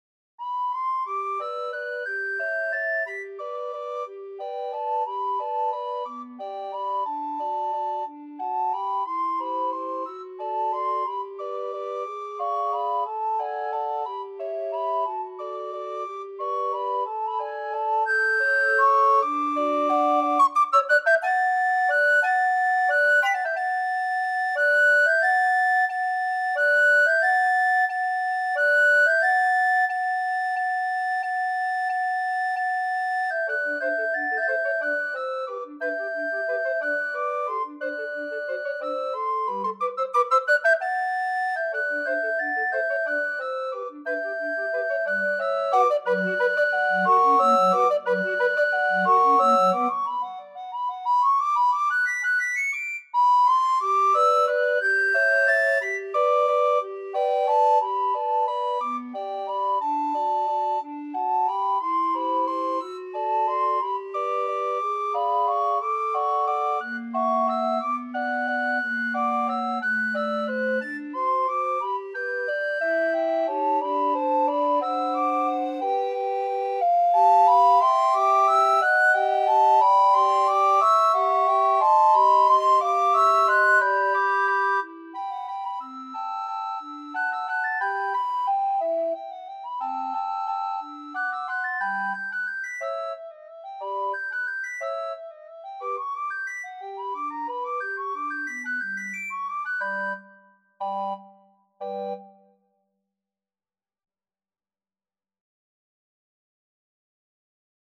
Free Sheet music for Recorder Quartet
Soprano RecorderAlto RecorderTenor RecorderBass Recorder
G major (Sounding Pitch) (View more G major Music for Recorder Quartet )
Tempo di Valse .=c.60 .=c.60
3/4 (View more 3/4 Music)
Classical (View more Classical Recorder Quartet Music)